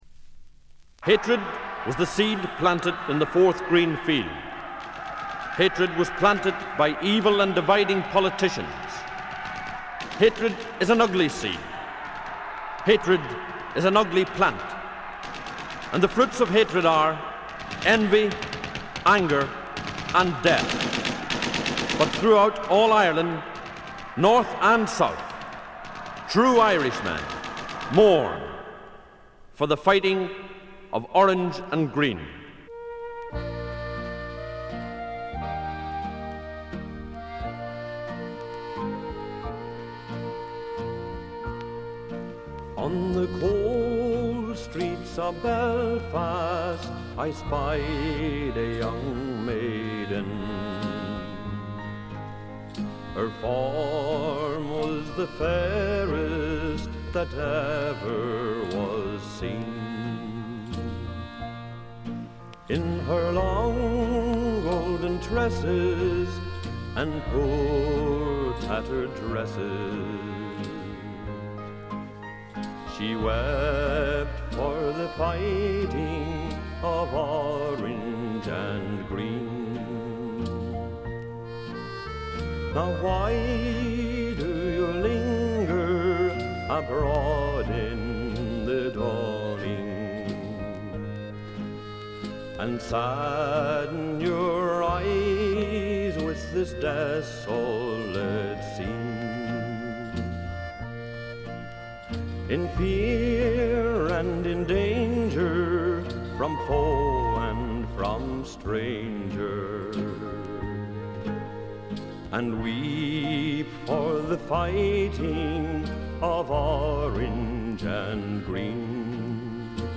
バックグラウンドノイズ、チリプチ、プツ音等多め大きめ。ところどころで周回ノイズもあり。
あとは鼓笛隊的な音が好きなマニアかな？？
試聴曲は現品からの取り込み音源です。
Recorded At - Trend International